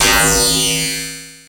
Video Game Transition Effect